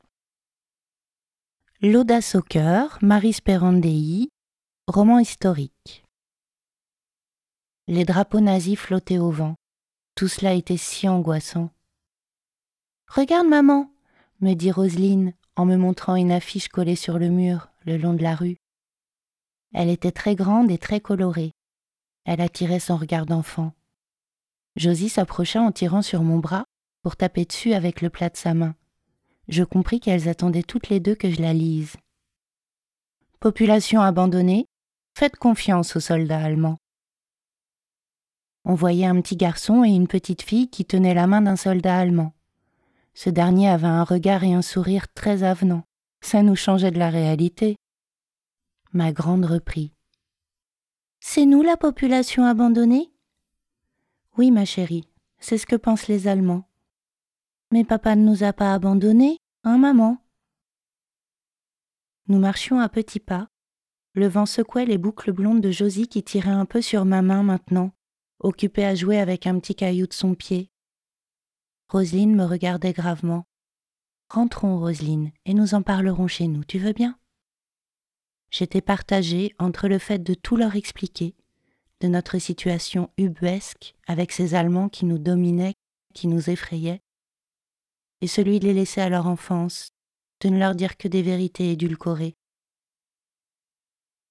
Roman historique — dialogue
Voix féminine, échanges incarnés entre personnages
demo-voix-roman-historique-l-audace-au-coeur.mp3